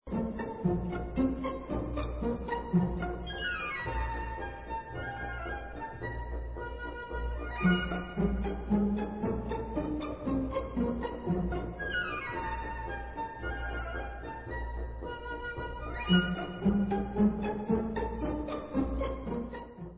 Allegro moderato